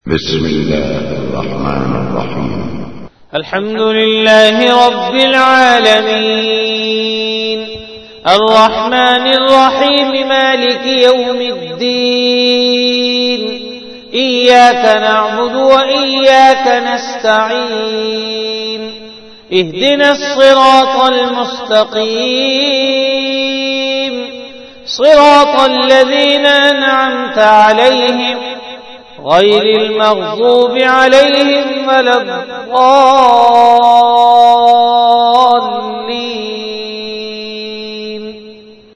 CategoryTilawat
Event / TimeAfter Isha Prayer